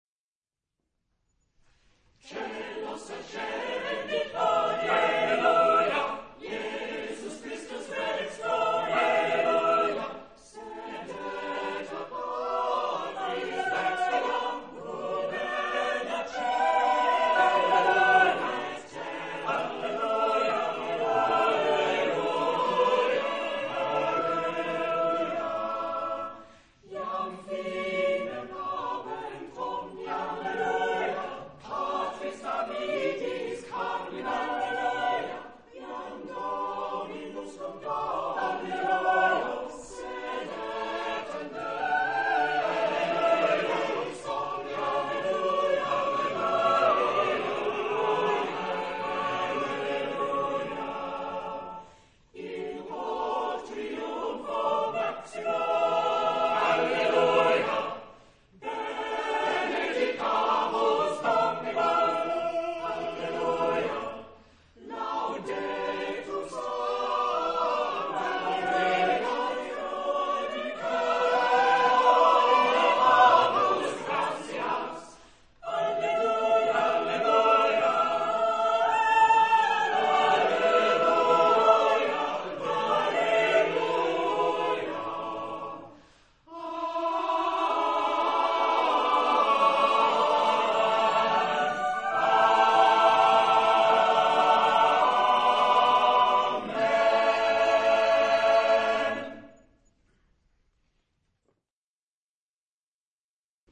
Genre-Style-Form: Motet ; Cycle ; Sacred
Type of Choir: SSAATTBB  (8 double choir voices )
Tonality: A major